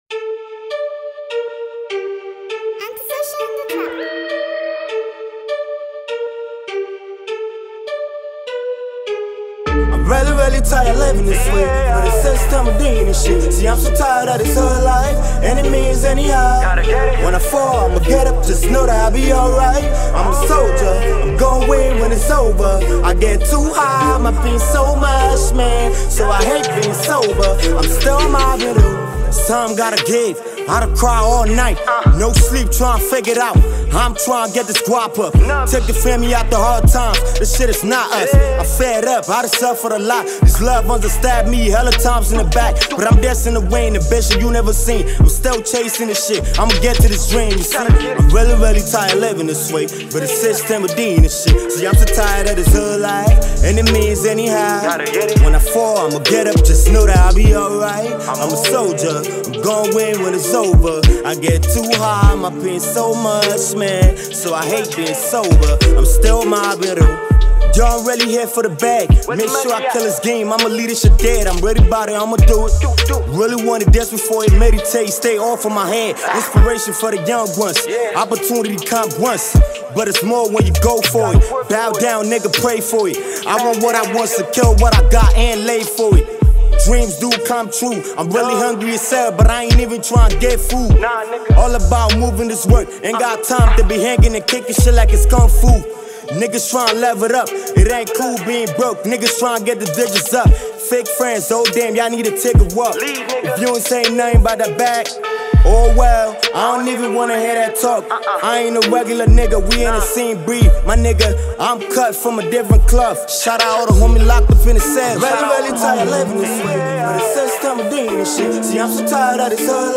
high core rap song